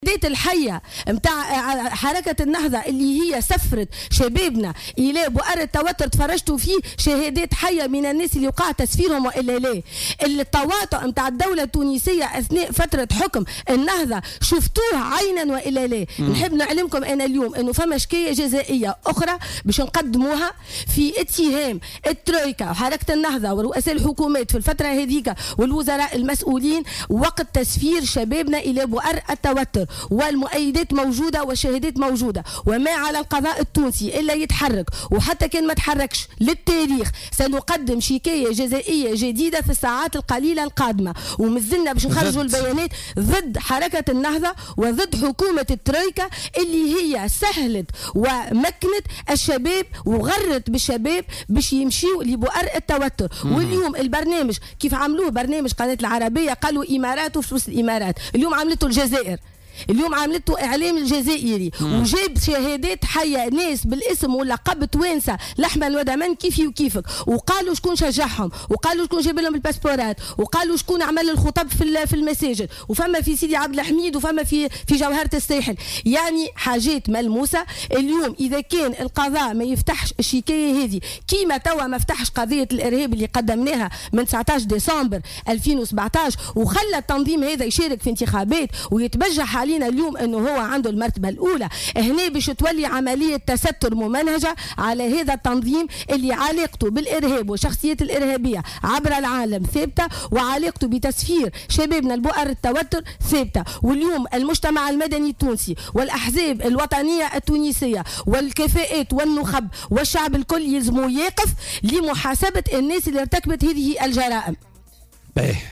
وأضافت ضيفة "بوليتيكا " على "الجوهرة أف أم" أن الشكوى ترتكز على مؤيدات وشهادات موجودة ضد حركة النهضة وحكومة الترويكا التي سهلت عملية تسفير الشباب إلى بؤر التوتر انطلاقا من شهادات حية بثها تلفزيون الشروق الجزائري .